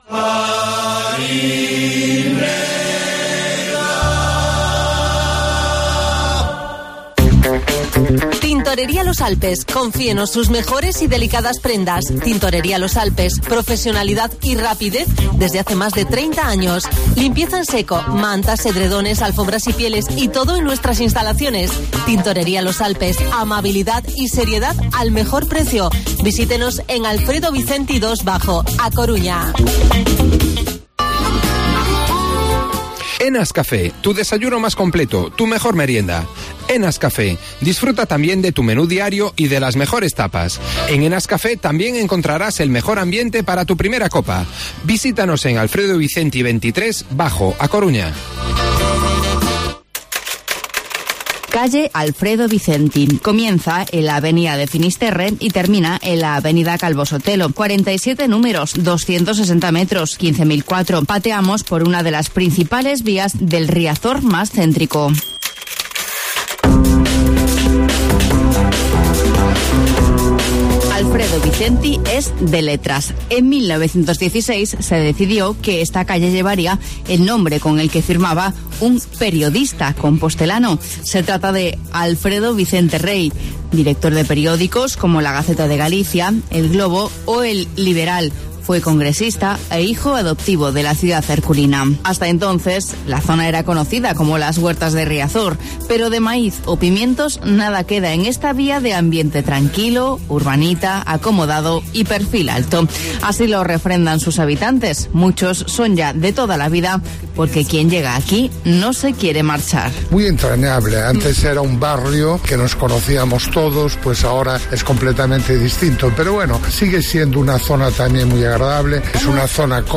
Escucha el reportaje y mira la galería de fotos sobre esta calle
Espacio emitido el 22/5/18 en COPE MÁS Coruña 99.9 FM